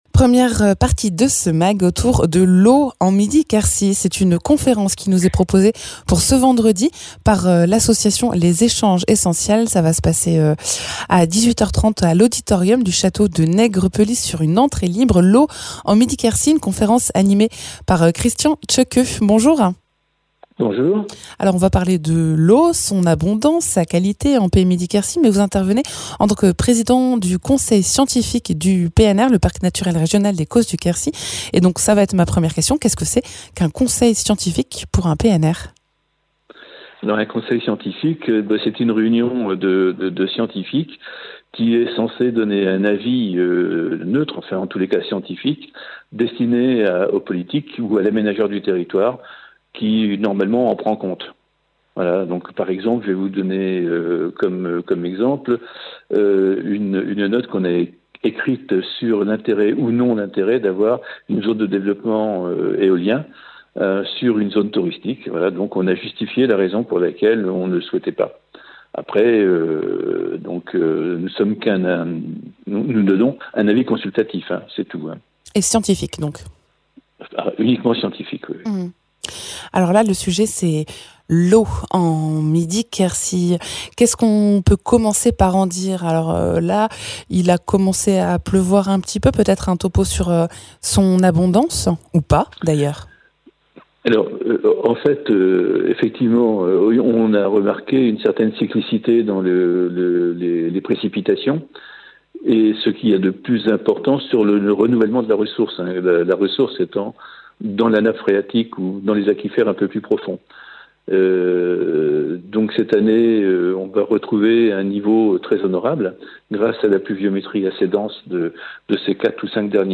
Interviews
Conférence vendredi soir à l’auditorium du château de Nègrepelisse, organisée par l’association "Les échanges essentiels", autour du thème de l’eau.